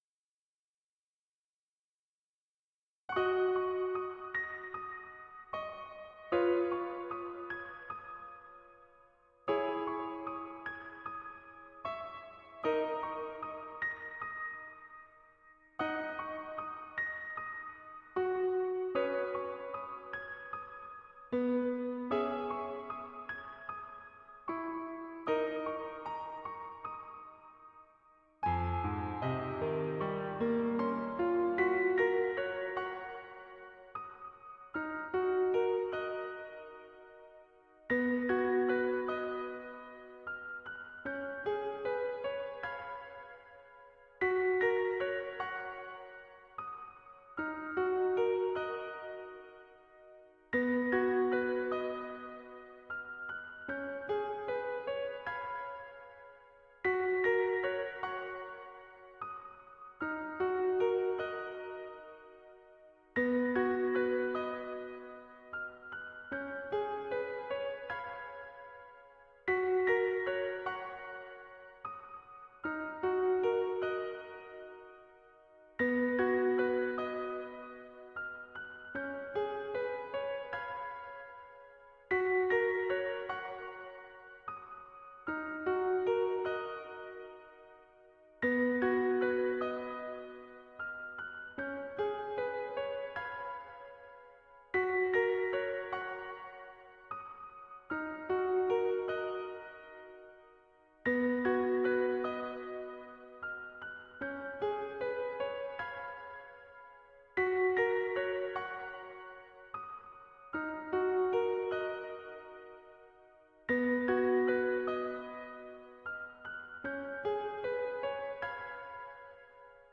【オフボーカル音源（mp3）】